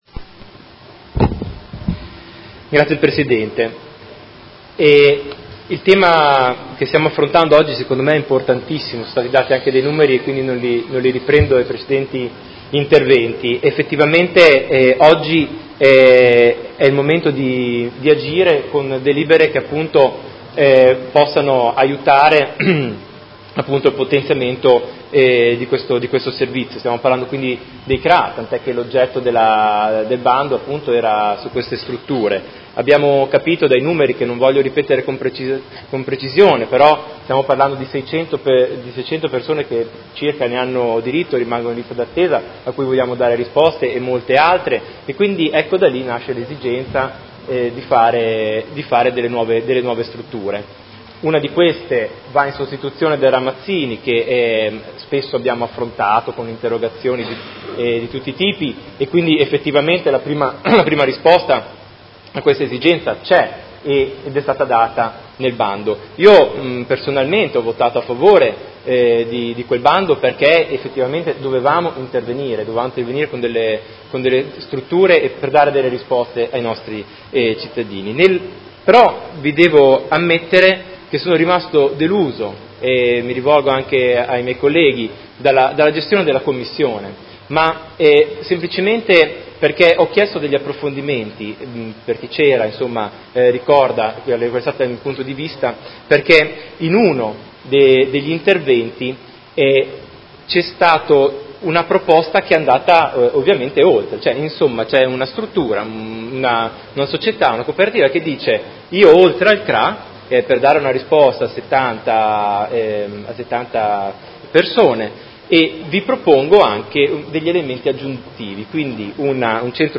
Seduta del 18/10/2018 Dibattito.
Audio Consiglio Comunale